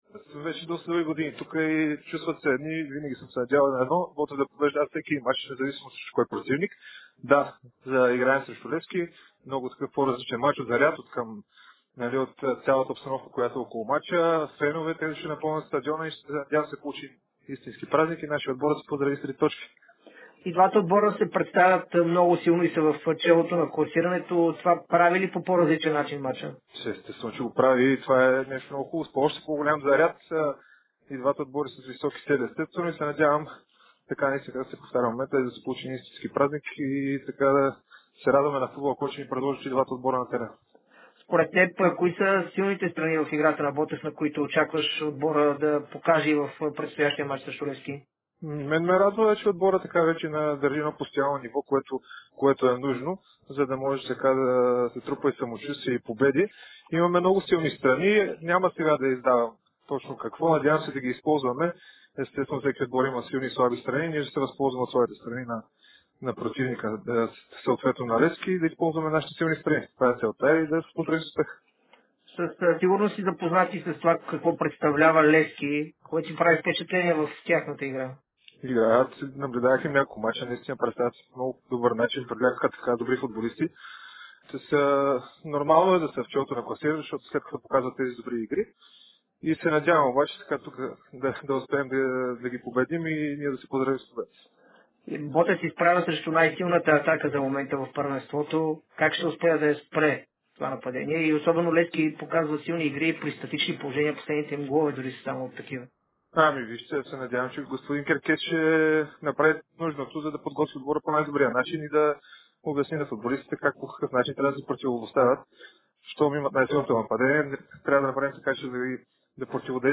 ексклузивно пред Дарик и dsport преди Ботев Пд - Левски